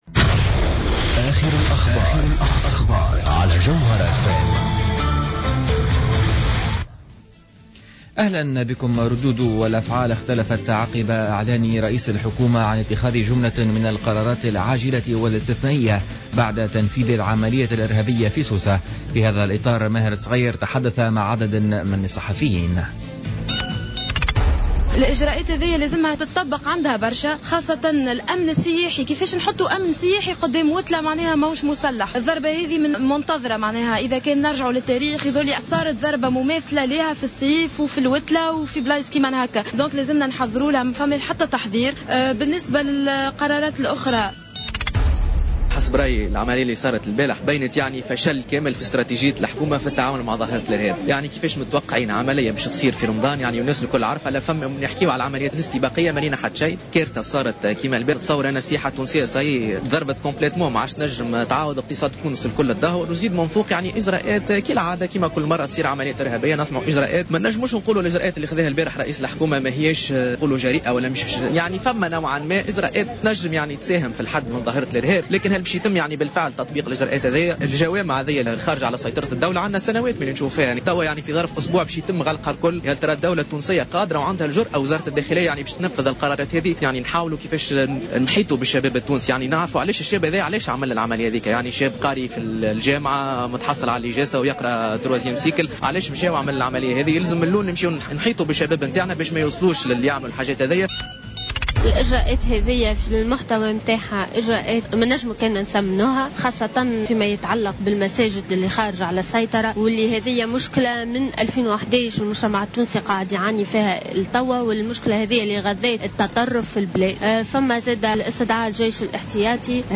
نشرة أخبار الخامسة مساء ليوم السبت 27 جوان 2015